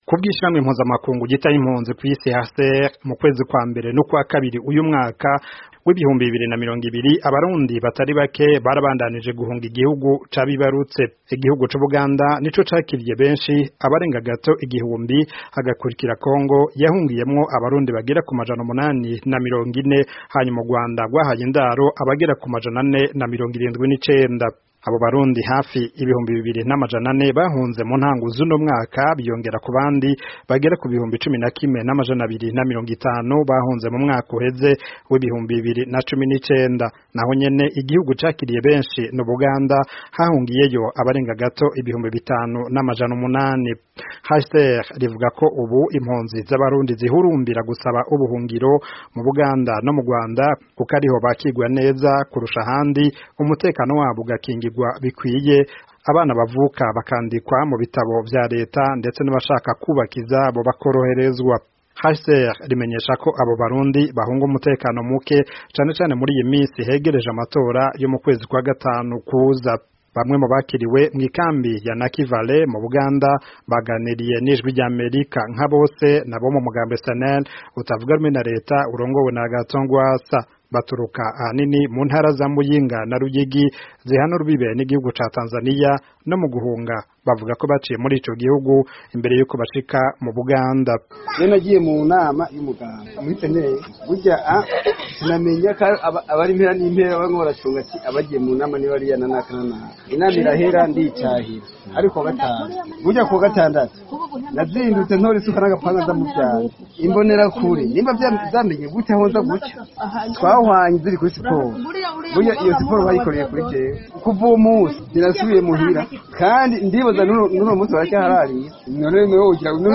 Bamwe mu babakiriwe mw’ikambi ya Nakivale mu Buganda baganiriye n'Ijwi ry'Amerika nka bose n’abo mu mugambwe CNL utavuga rumwe na Reta urongowe na Agathon Rwasa.